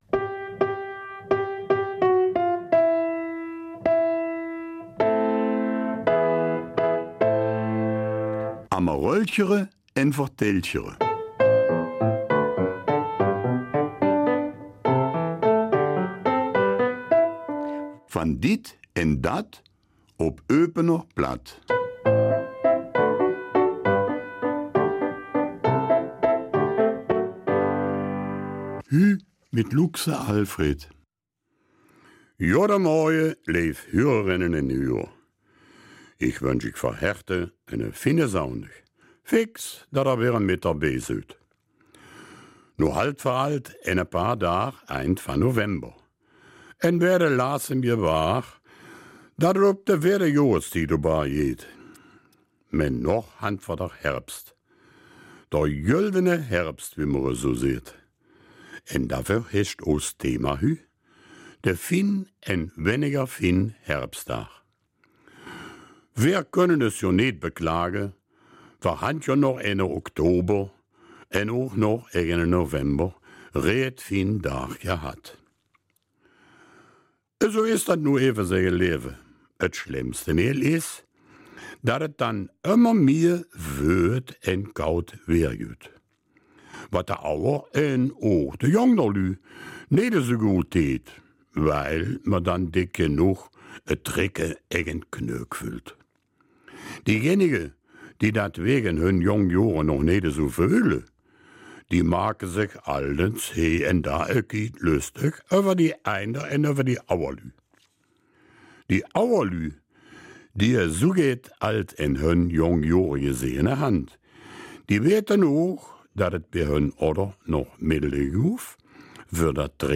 Eupener Mundart - 22. November